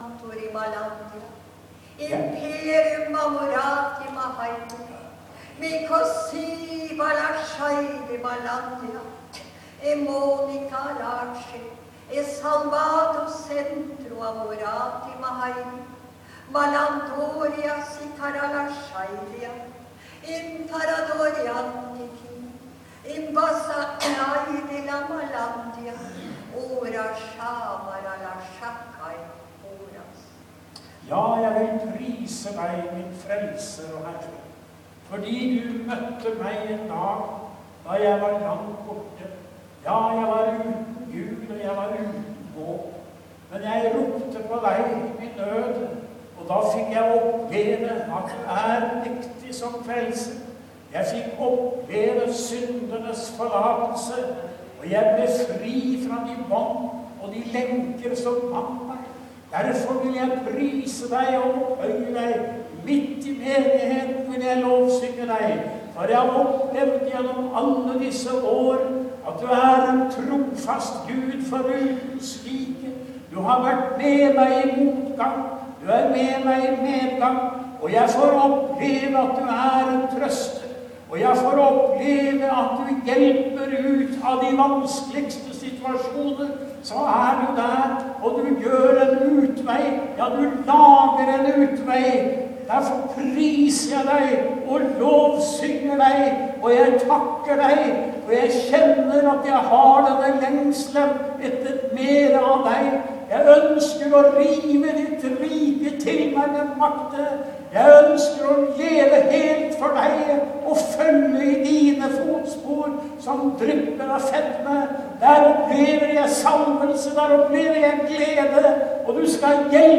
Tungetale